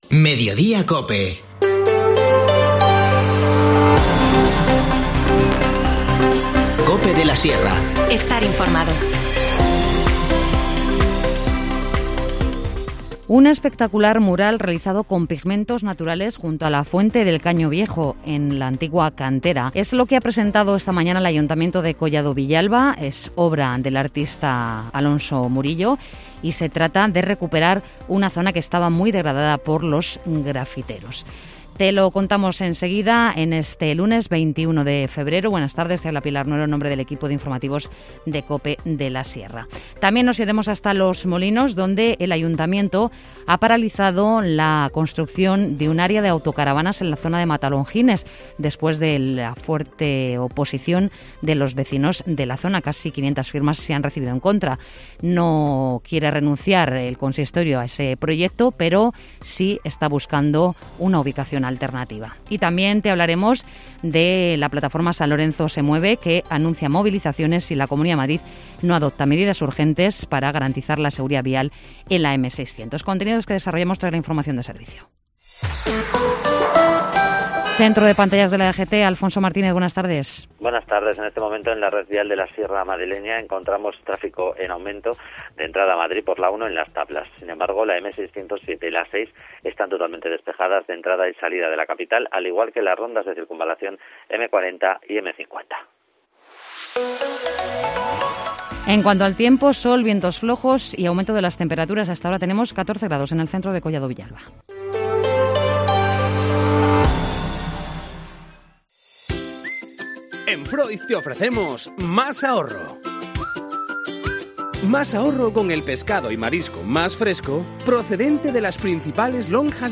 Informativo Mediodía 21 febrero